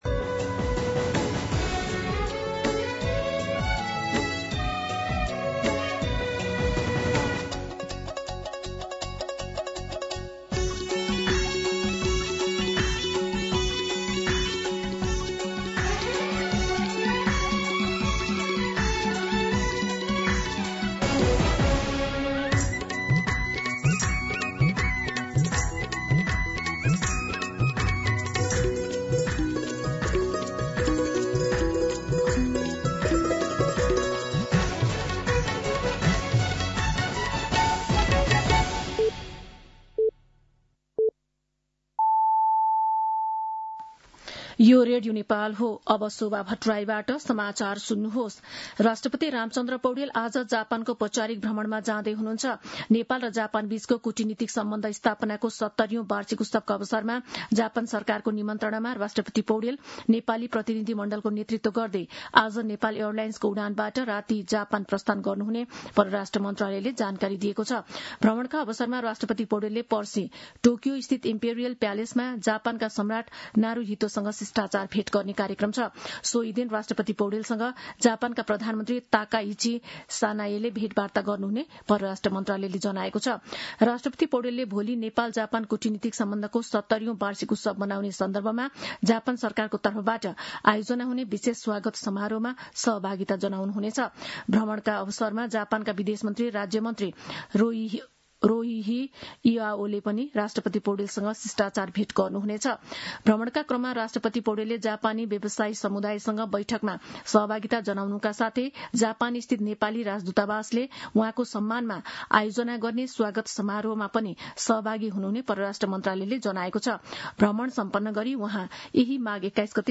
मध्यान्ह १२ बजेको नेपाली समाचार : १८ माघ , २०८२